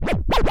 scratch05.wav